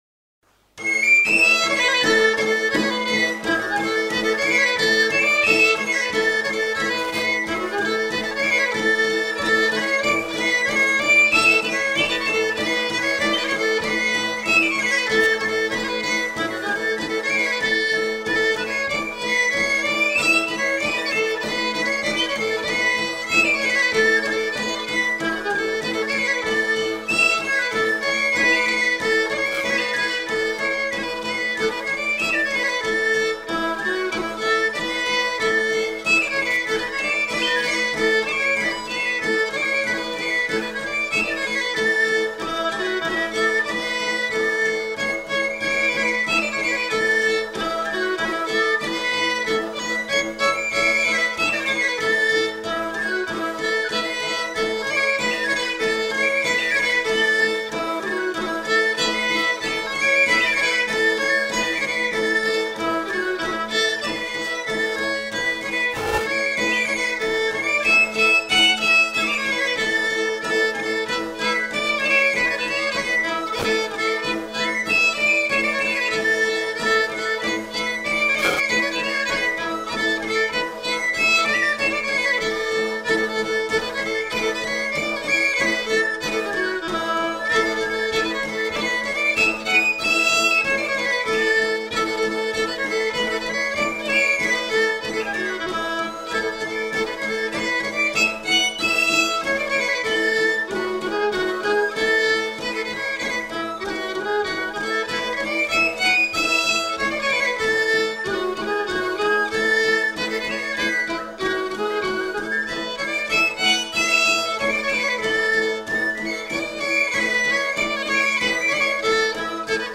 Aire culturelle : Béarn
Lieu : Bielle
Genre : morceau instrumental
Instrument de musique : violon ; flûte à trois trous ; tambourin à cordes
Danse : mochico